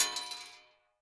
main Divergent / mods / Bullet Shell Sounds / gamedata / sounds / bullet_shells / pistol_metal_8.ogg 20 KiB (Stored with Git LFS) Raw Permalink History
pistol_metal_8.ogg